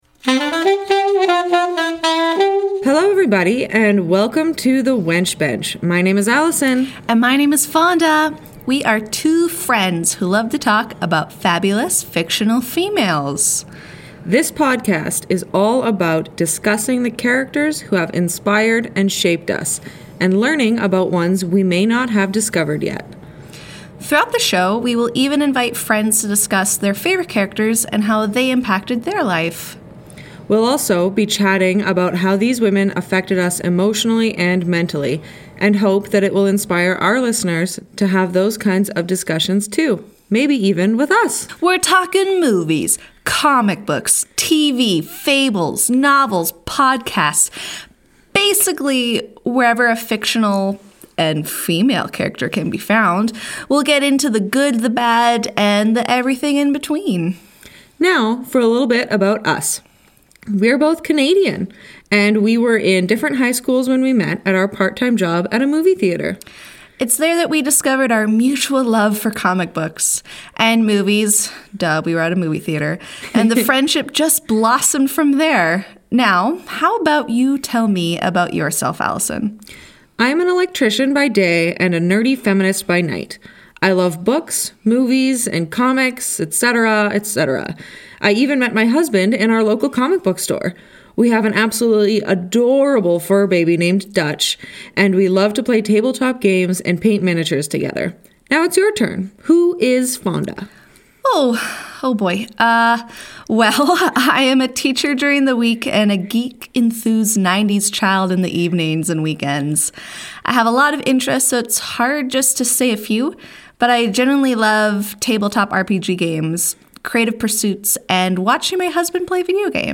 A monthly podcast about fabulous, fictional females. Listen to two gal pals take turns talking about women from fictional stories, shows, movies, comics, folk lore and more!